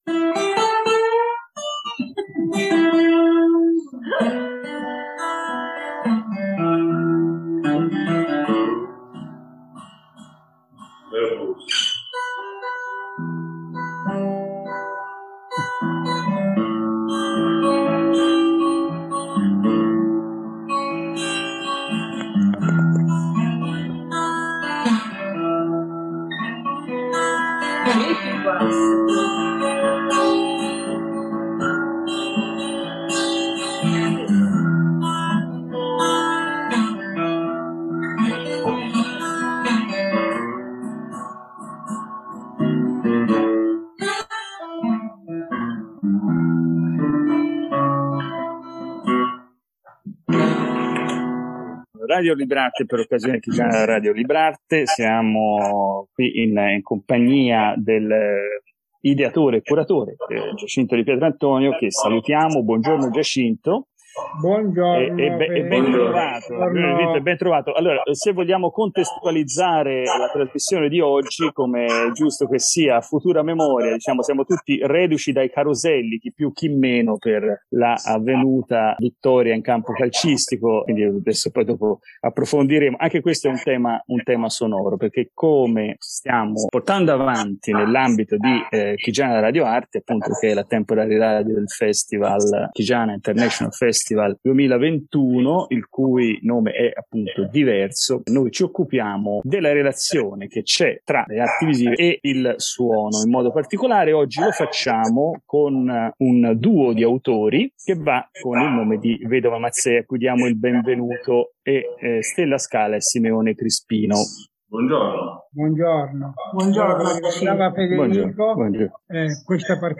During the episode the following works of the well-known duo are broadcast in ascending order:
E’ il ronzio di una zanzara che canta un Blues stonato.